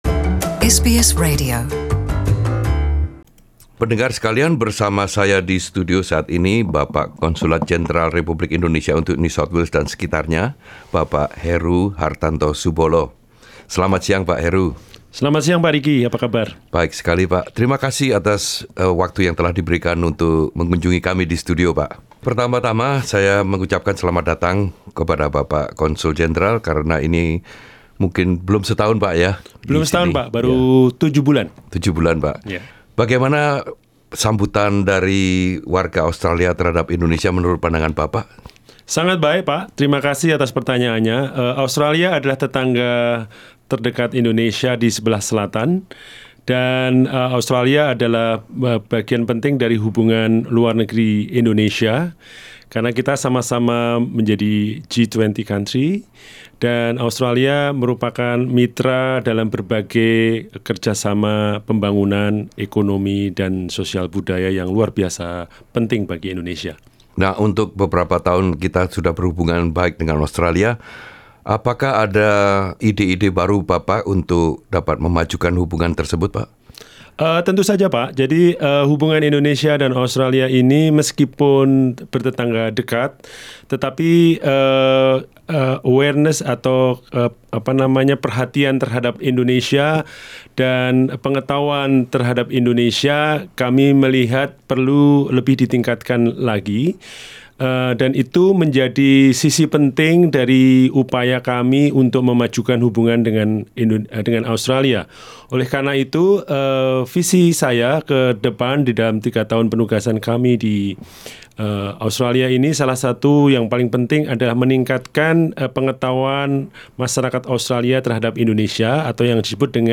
Saat berkunjung ke SBS Radio, Konsul Jenderal RI untuk NSW, QLD and SA Bpk Heru Hartanto Subolo berbincang tentang masa jabatannya yang baru di Australia.